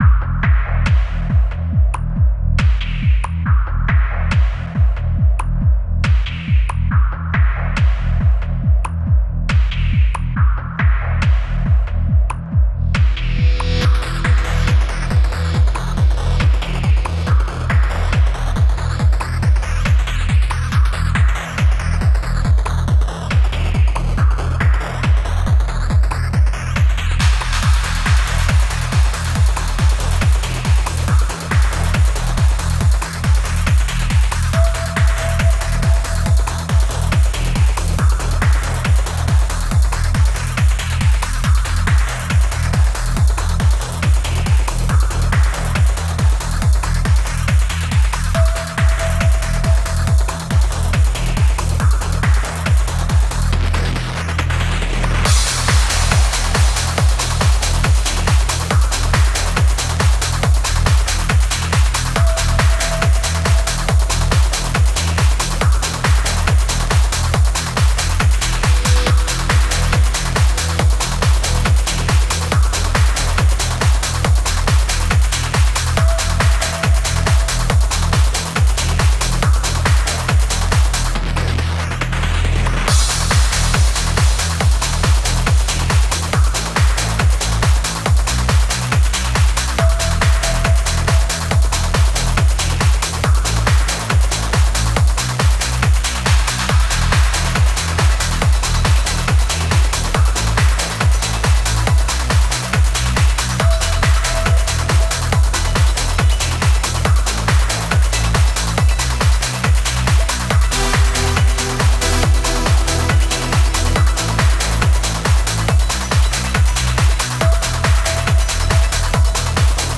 Slightly Dirty Emotional Trance.